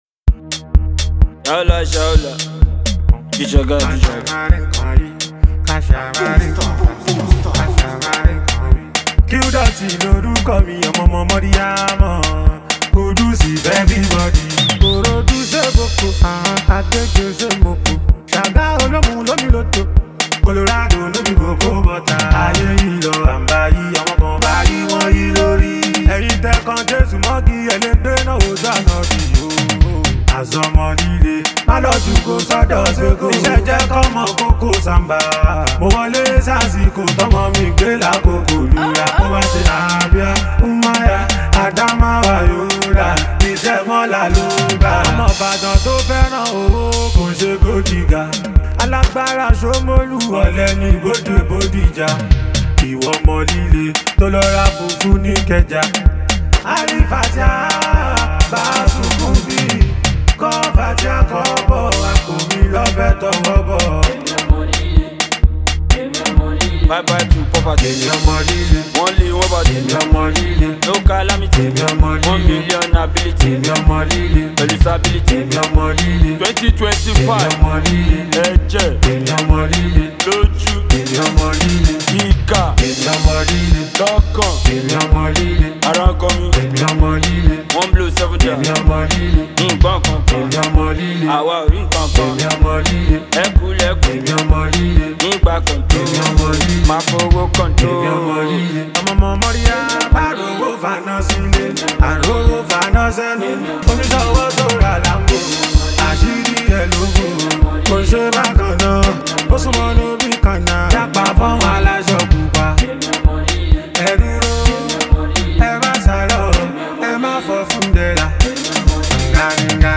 Nigerian street-hop